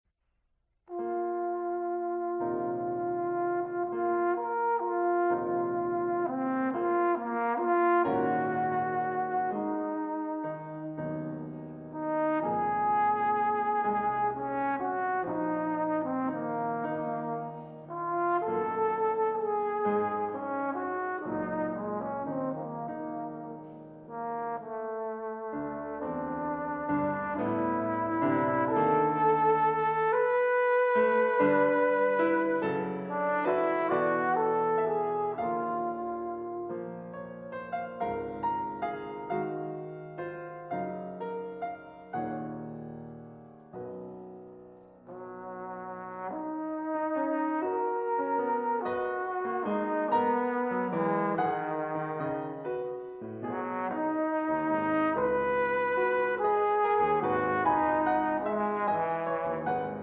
trumpet and alto horn